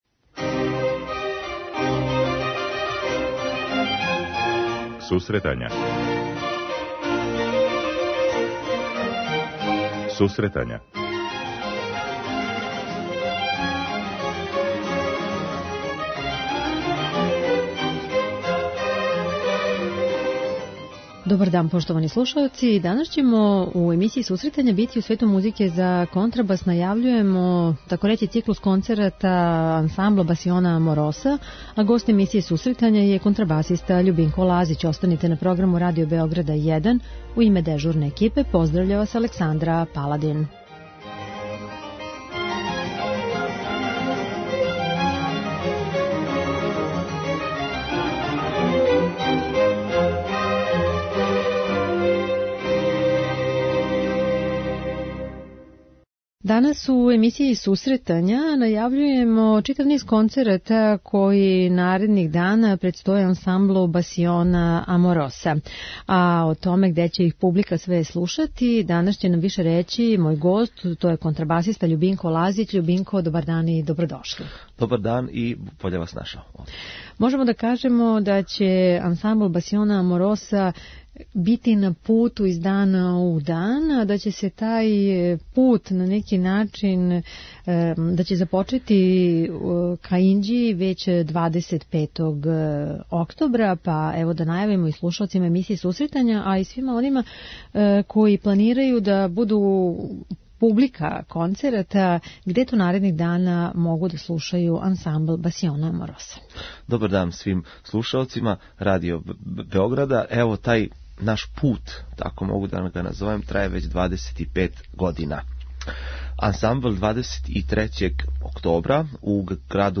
преузми : 10.09 MB Сусретања Autor: Музичка редакција Емисија за оне који воле уметничку музику.